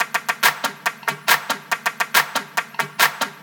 DDWV CLAP LOOP 1.wav